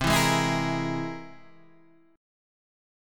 C Augmented 7th